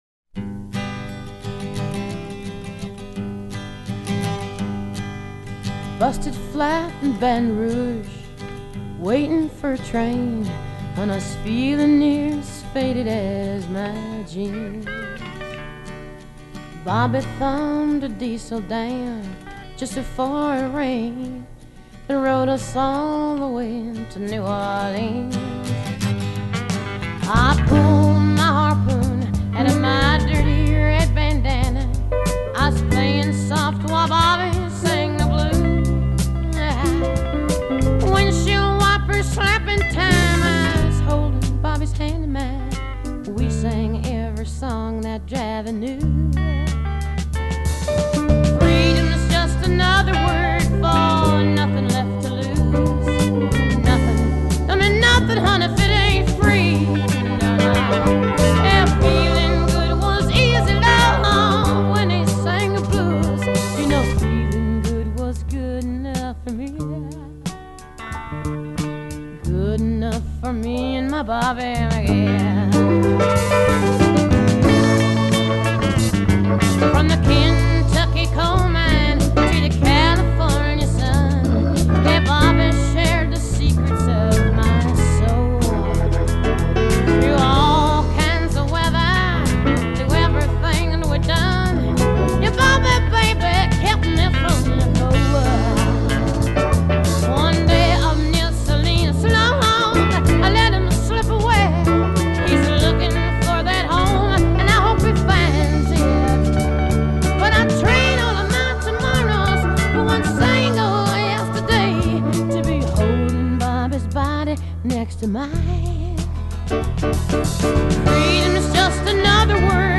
[あえてのMono Single ver.]